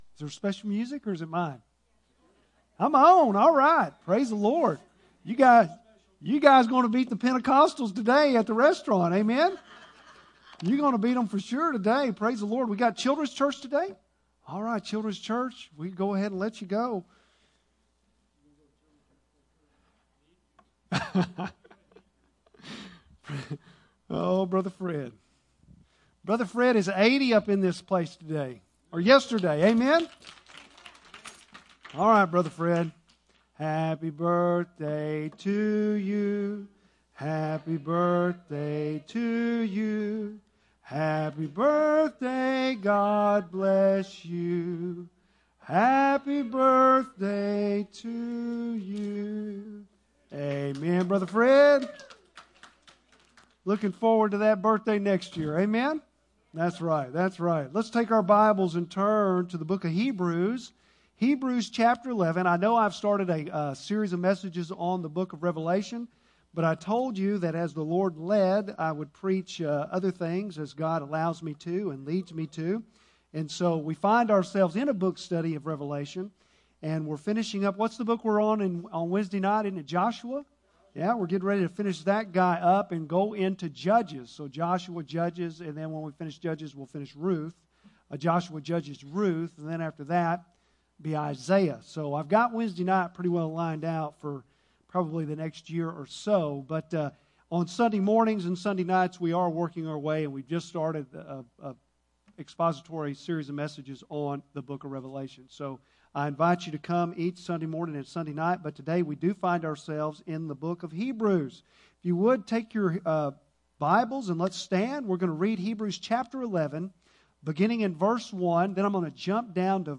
Bible Text: Hebrews 11:1, 6, 32-40 | Preacher